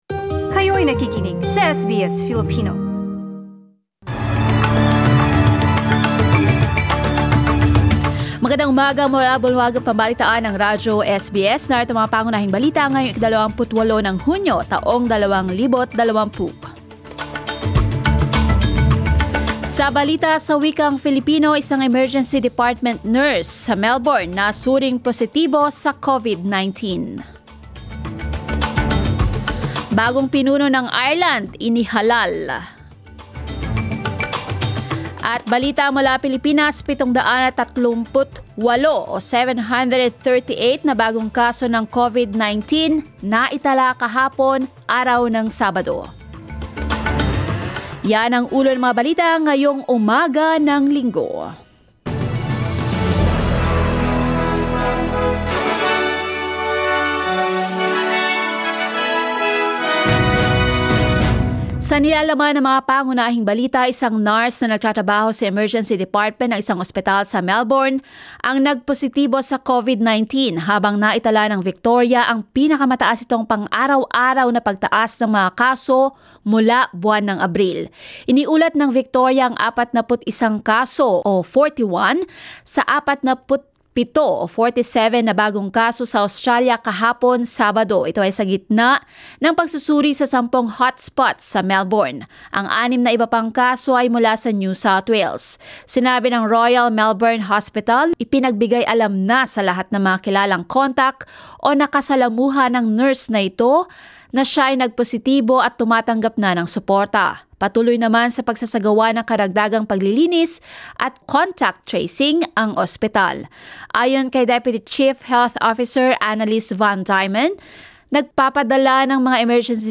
SBS News in Filipino, Sunday 28 June